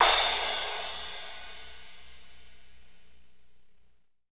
CYM P C CR0T.wav